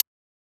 ClosedHH Funk 2.wav